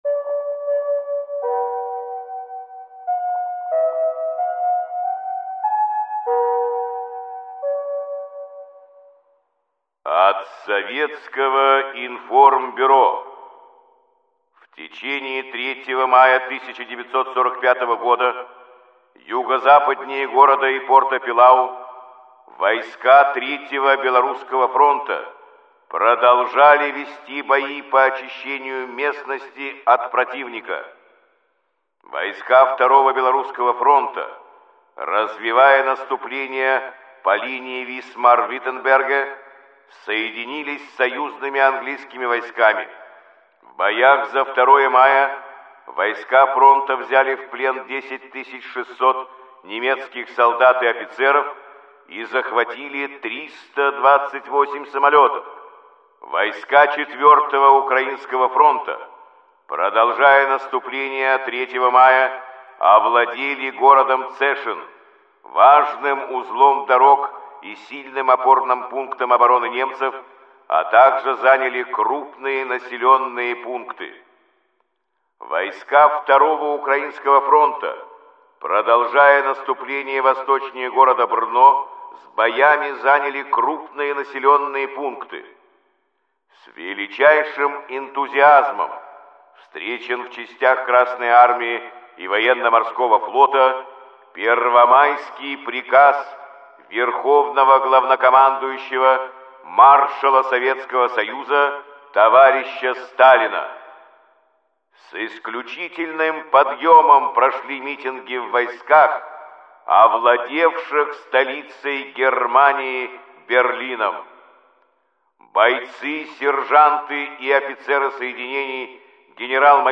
Каждый день – это хронология самых важных событий и подвигов героев Великой Отечественной войны, рассказанная в сообщениях СОВИНФОРМБЮРО голосом Юрия Борисовича Левитана.